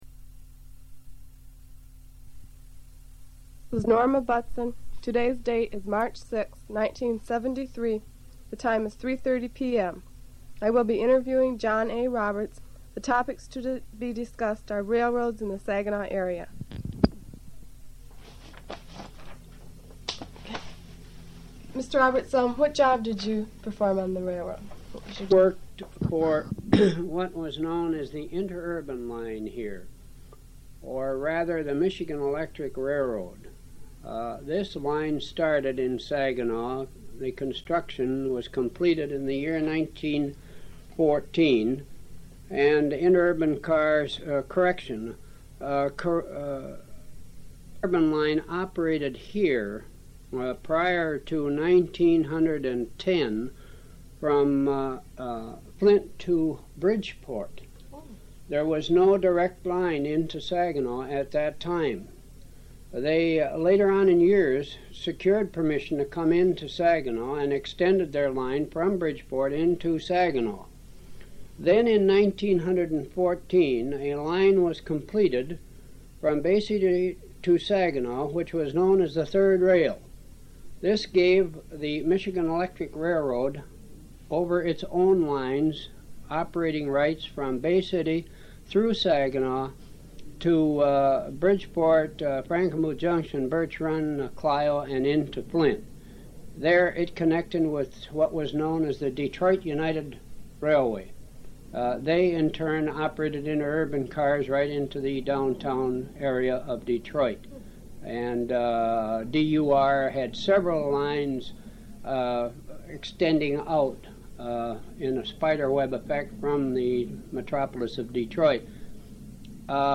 Oral History
Original Format Audiocassette